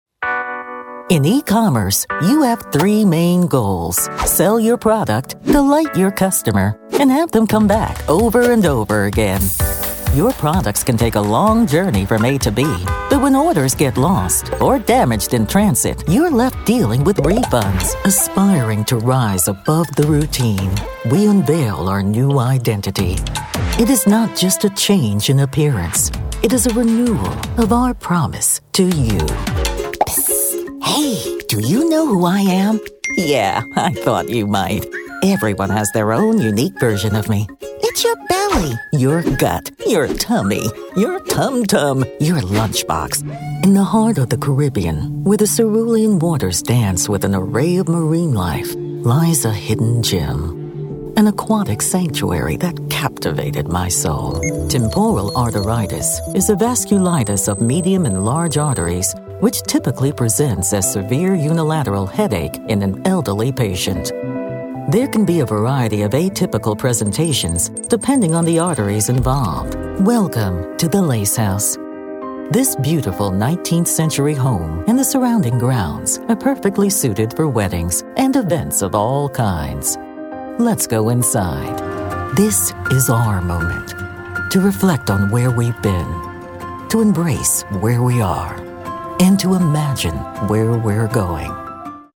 Female
American English (Native)
Assured, Authoritative, Character, Confident, Corporate, Engaging, Friendly, Gravitas, Smooth, Warm, Versatile
Microphone: Rode N1 - 5th Gen, Re 27, MXL 990